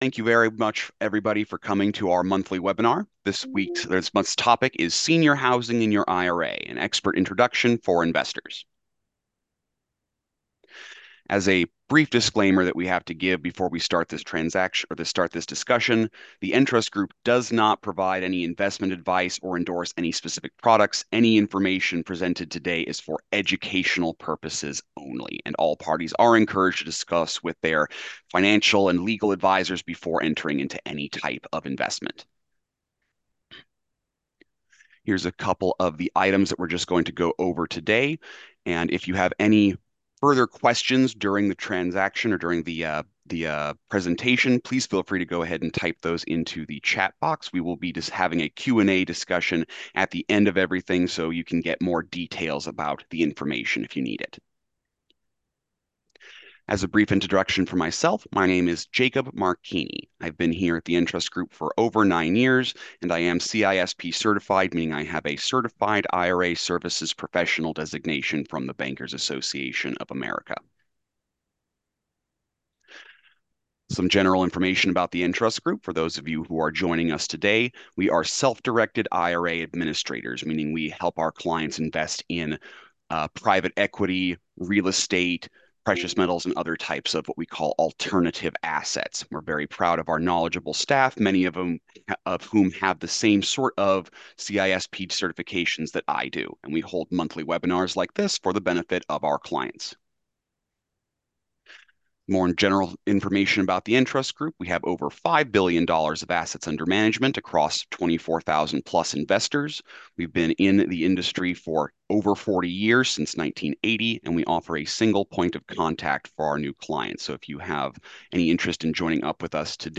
Webinar_September_2025_Audio_Replay.m4a